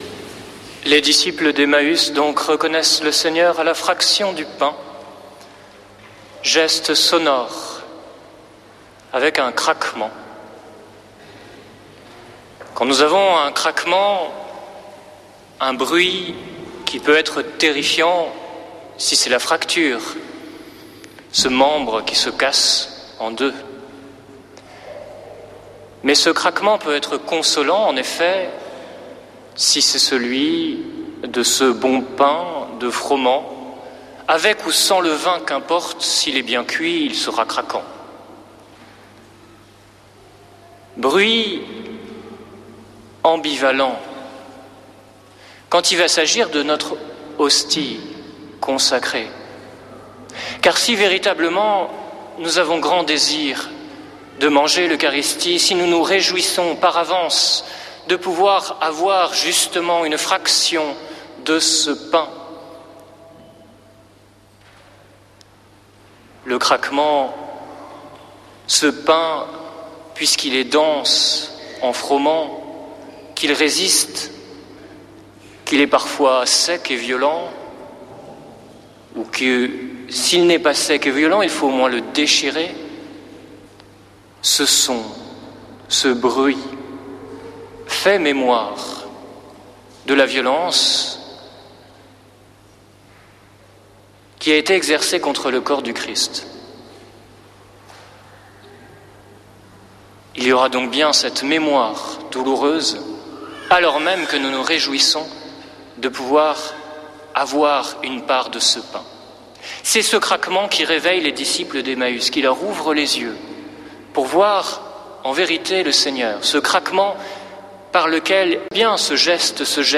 Messe depuis le couvent des Dominicains de Toulouse du 19 avr.